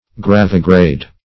gravigrade - definition of gravigrade - synonyms, pronunciation, spelling from Free Dictionary
Search Result for " gravigrade" : The Collaborative International Dictionary of English v.0.48: Gravigrade \Grav"i*grade\, a. [L. gravis heavy + gradus step.]